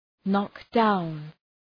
Προφορά
{‘nɒkdaʋn}